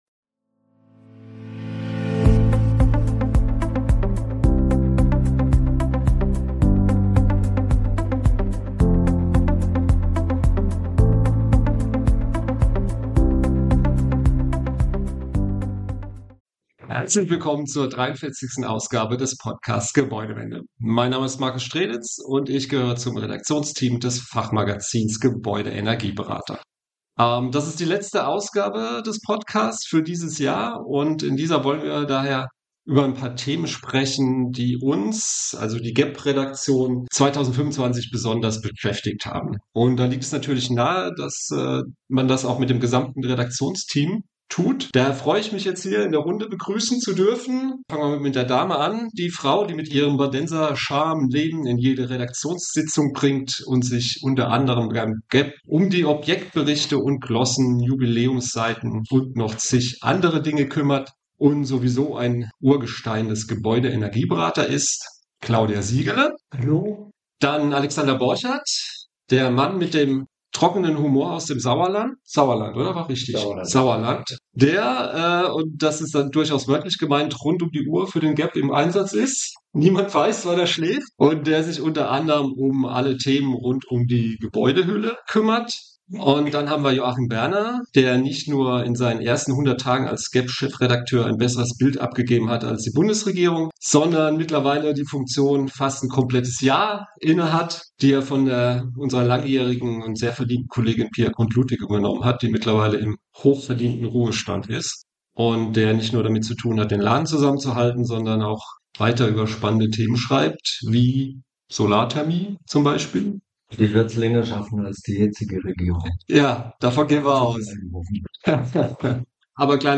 Über diese und weitere Fragen diskutiert das Redaktionsteam des Gebäude-Energieberater in seinem Rückblick auf das Jahr 2025.